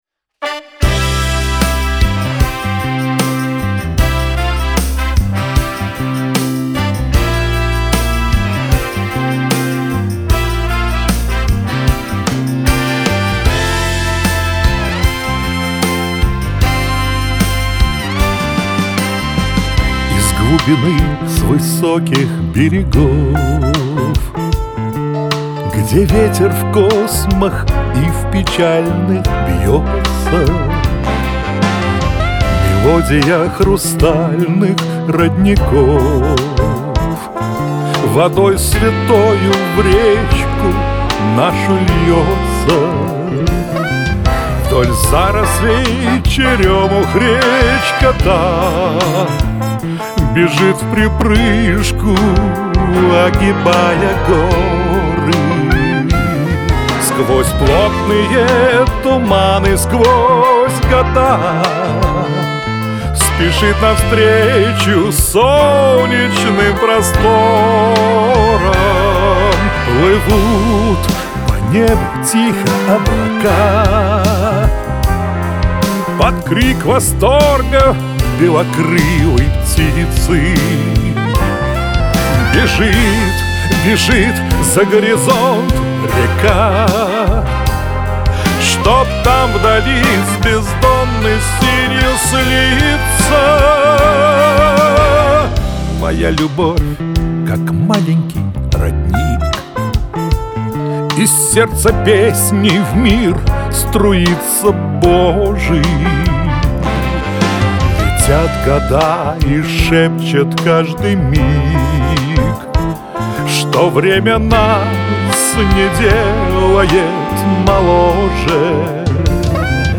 Оцените аранжировку
Аранжировка и частичное исполнение ( трубы/флюгельгорн) мои. Шансон в сопровождении... биг-бэнда)